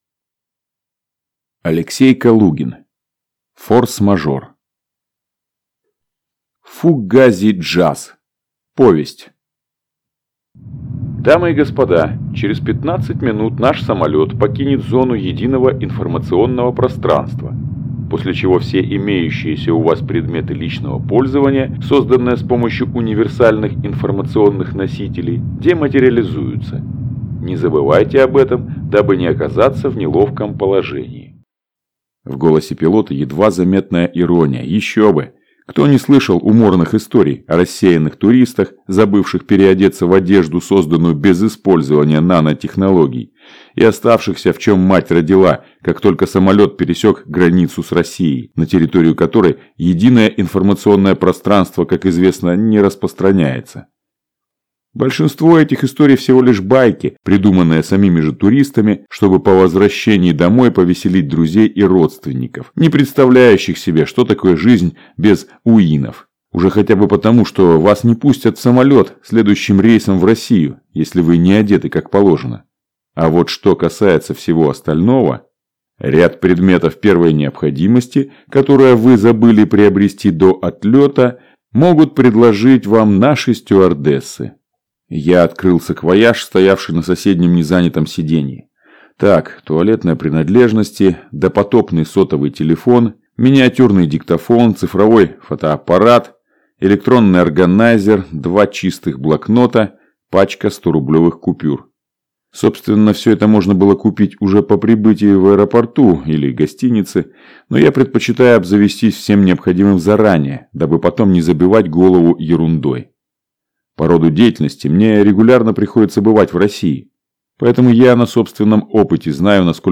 Аудиокнига Форс-мажор (сборник) | Библиотека аудиокниг